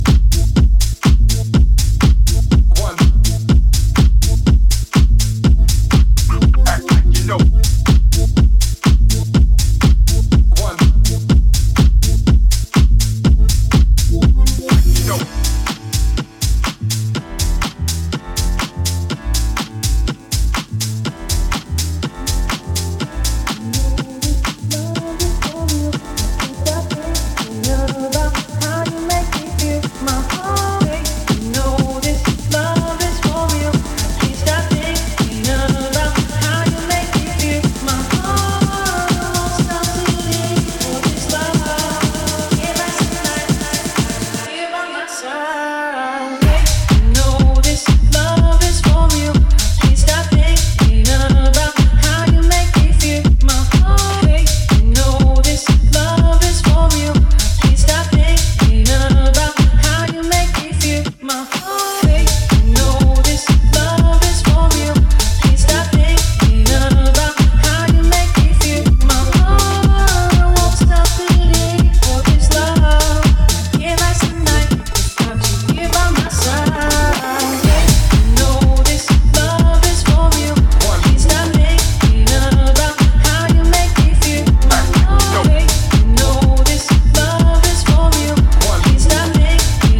ジャンル(スタイル) HOUSE / DEEP HOUSE